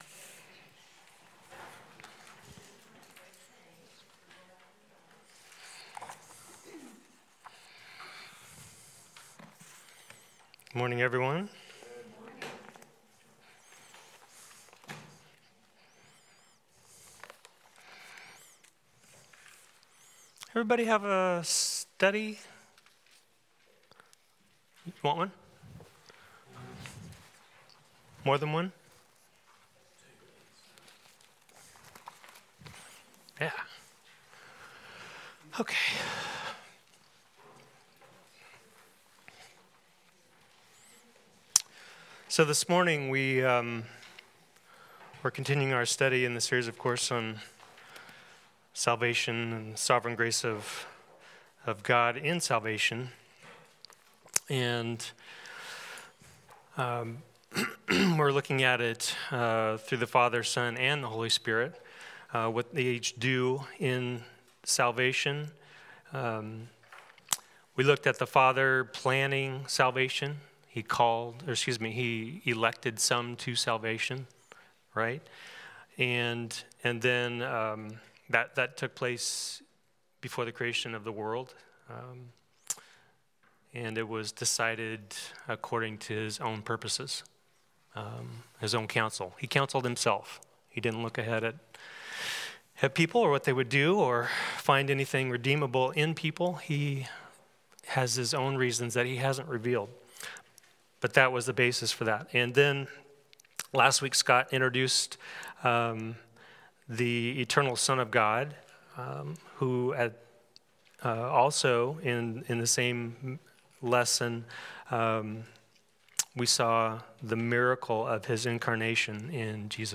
John 10:1-30 Service Type: Sunday School Jesus is the Good Shepherd who gave His life for the sheep.